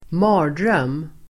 Uttal: [²m'a:r_dröm:]